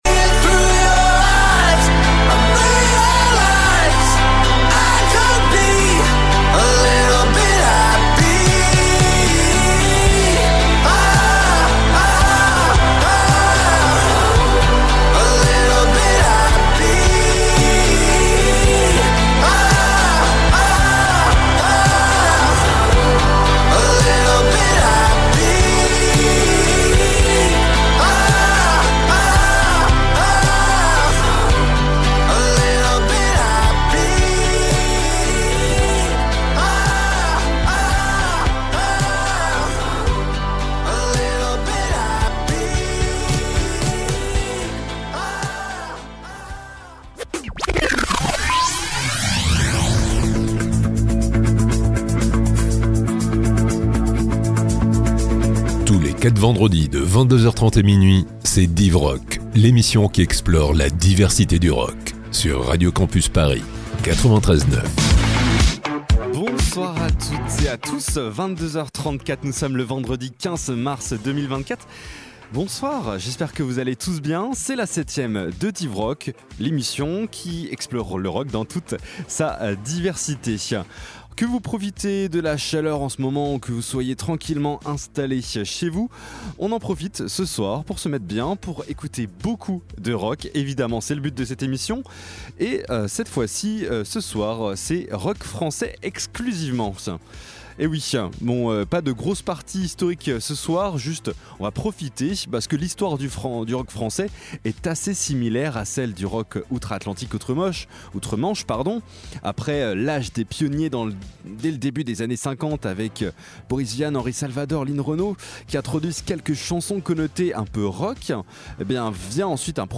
#7 - Rock français (00s/10s)
Pop & Rock